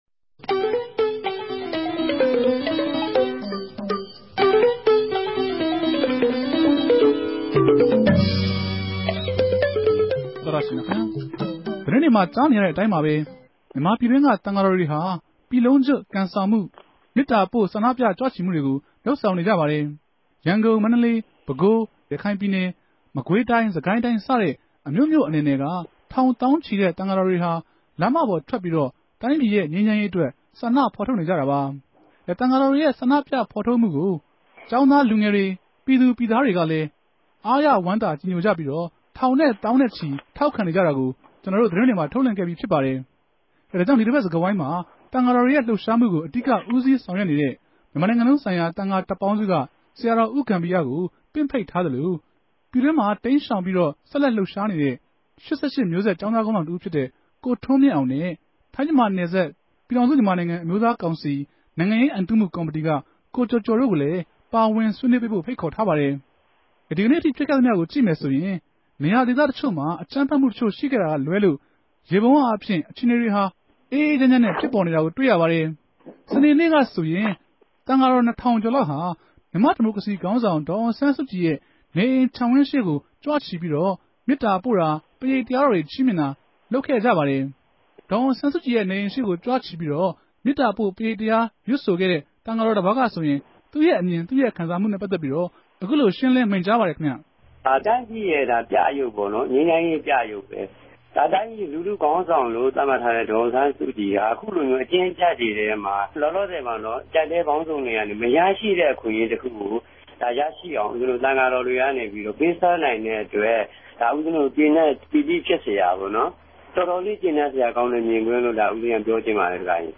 RFA ဝၝရြင်တန်႟ုံးခဵြပ် စတူဒီယိုထဲကနေ ဆက်သြယ်္ဘပီး ပၝဝင် ဆြေးေိံြးထားပၝတယ်။
တနဂဿေိံြ ဆြေးေိံြးပြဲစကားဝိုင်း